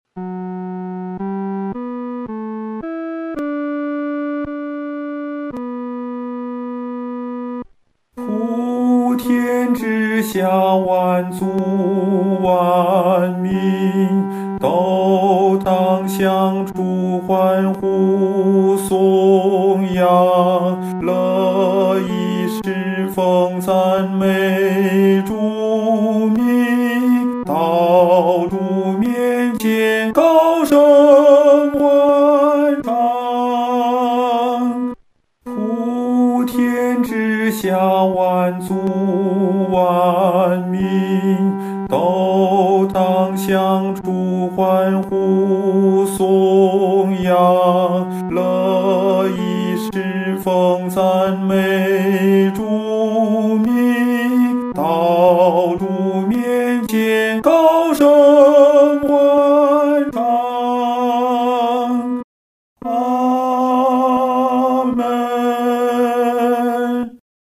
合唱
男高